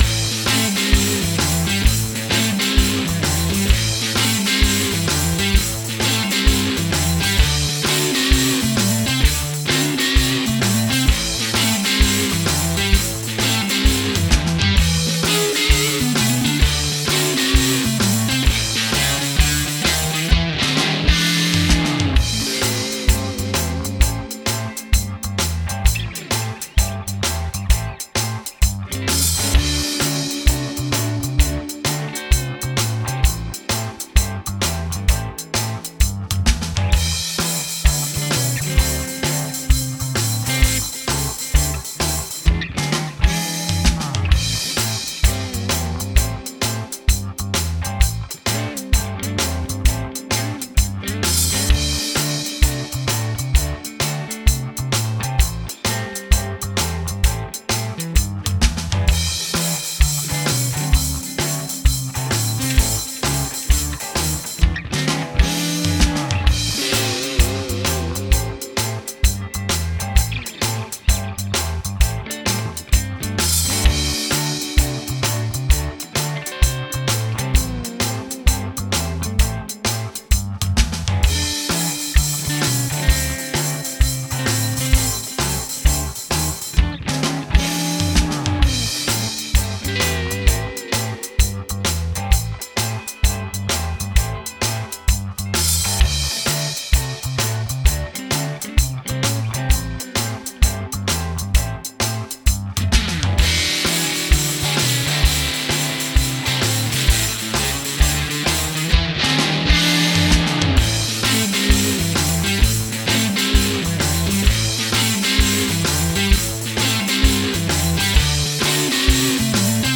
This particular jam track is in A minor, so […]